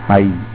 Dipthongs
They include ஐ ai